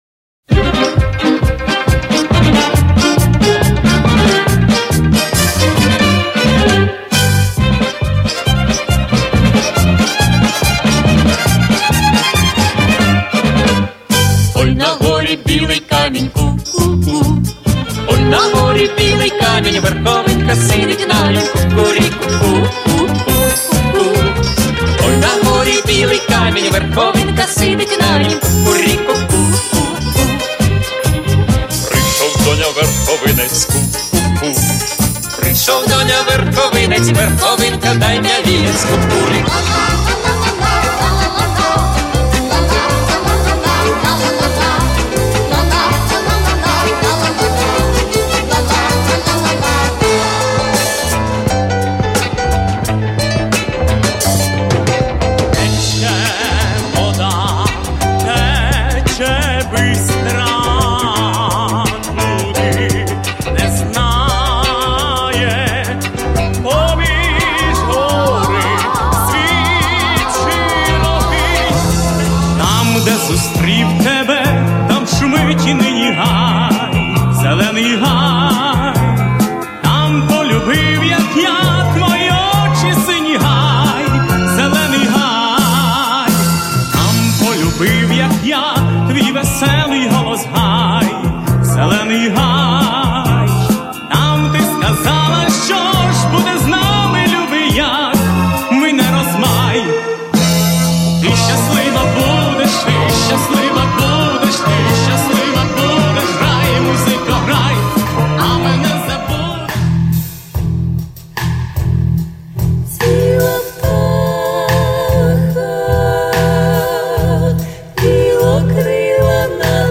Старые - добрые украинские песни: